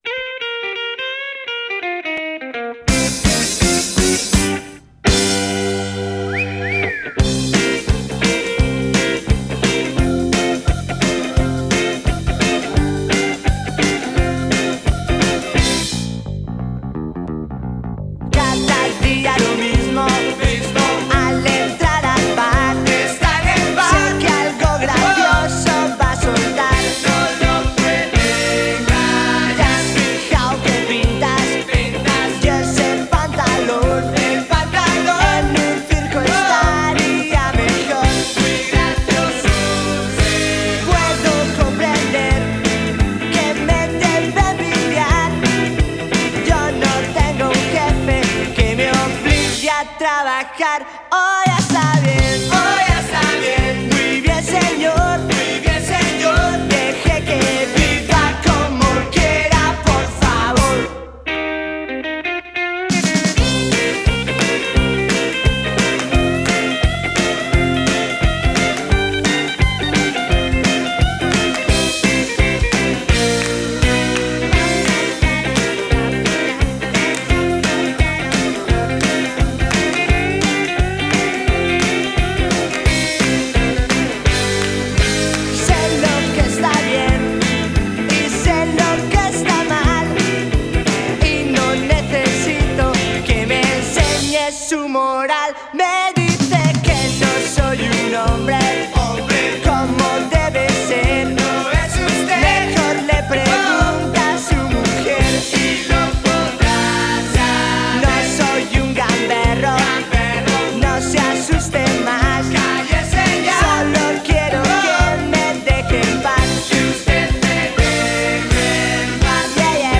totally 1965 beat sound